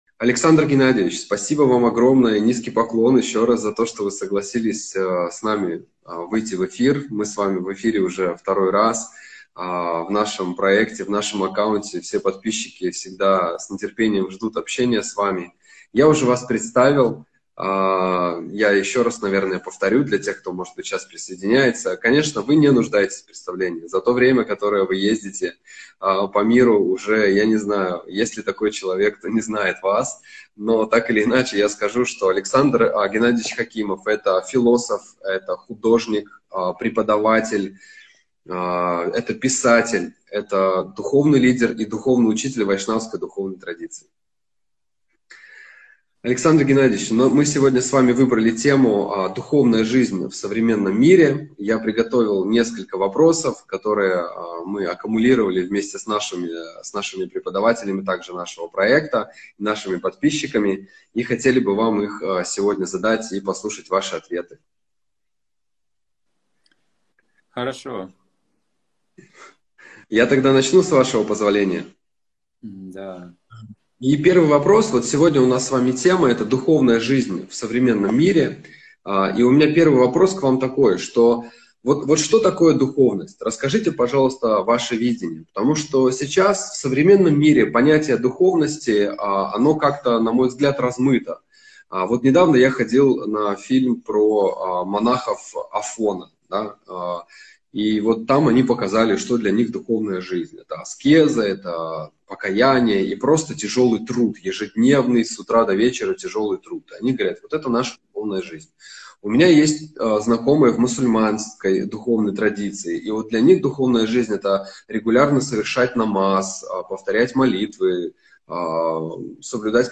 Алматы